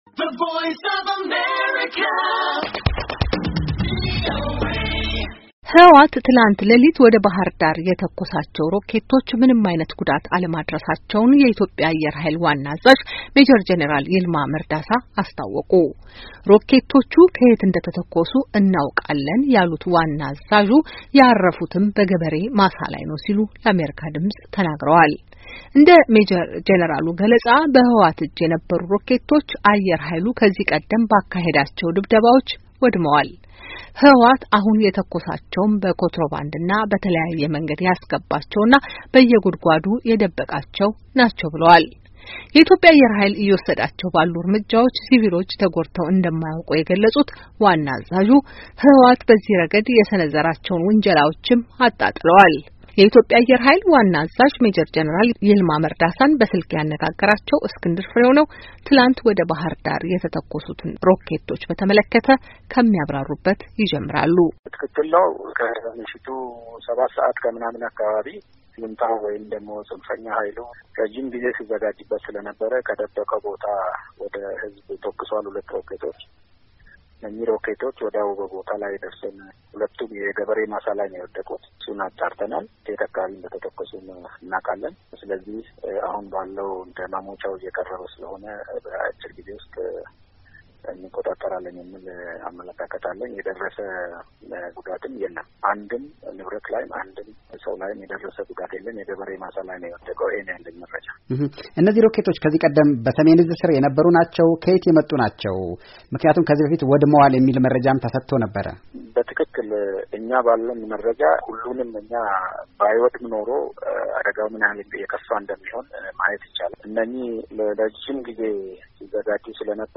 ቆይታ ከኢትዮጵያ አየር ኃይል አዛዥ ጋር
የኢትዮጵያ አየር ኃይል ዋና አዛዥ ሜጄር ጄነራል ይልማ መርዳሳ